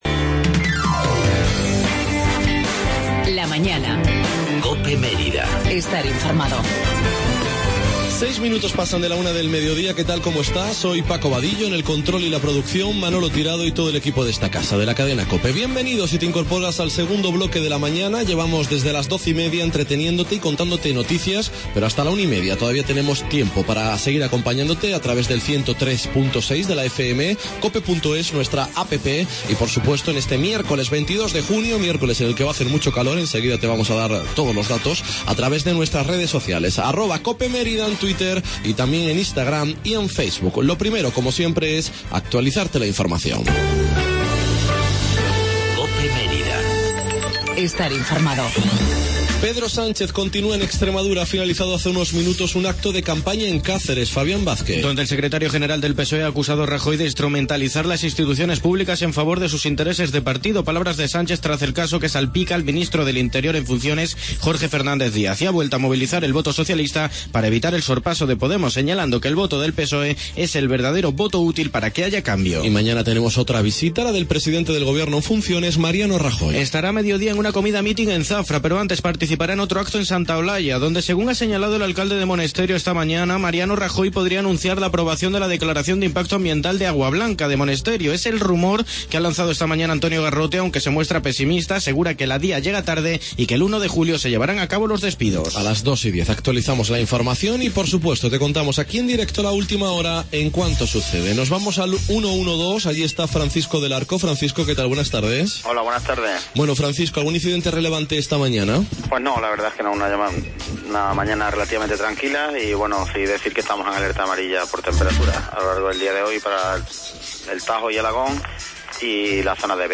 ESPECIAL TERTULIA EUROCOPE EN COPE MÉRIDA 22-06-16